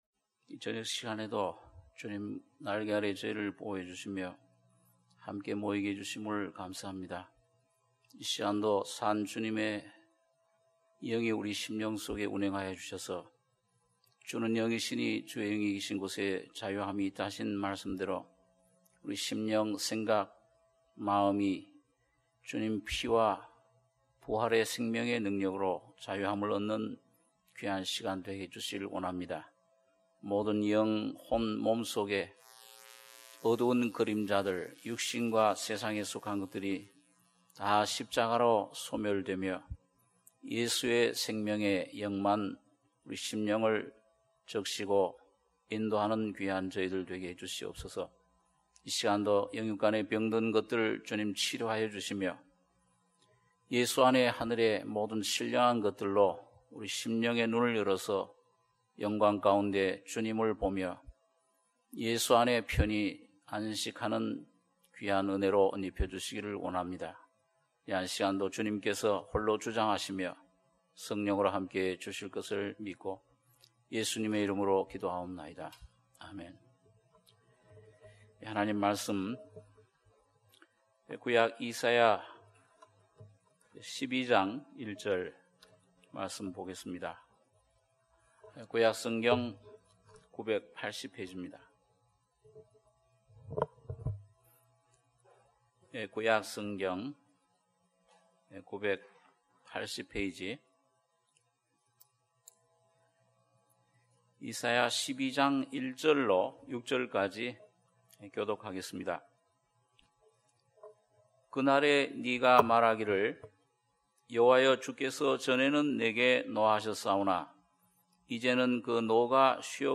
수요예배 - 이사야 12장 1절~6절